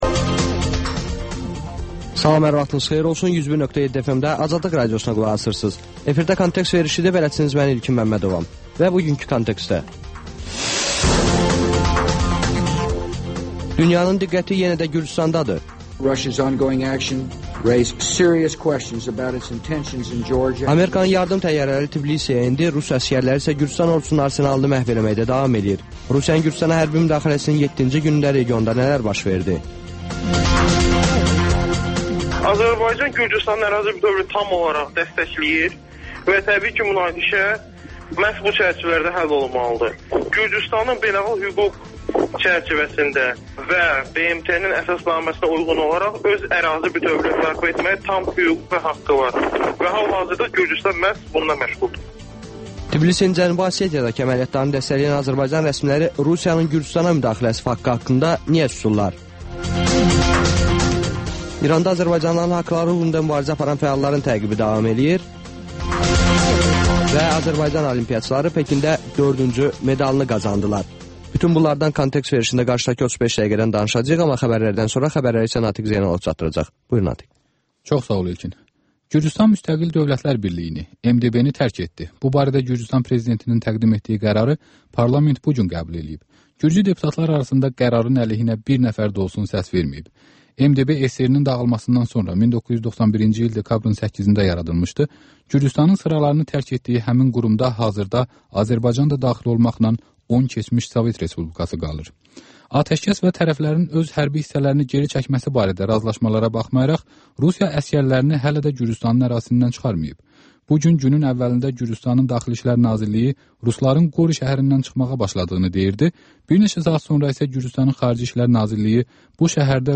Xəbərlər, müsahibələr, hadisələrin müzakirəsi, təhlillər, sonda TANINMIŞLAR: Ölkənin tanınmış simaları ilə söhbət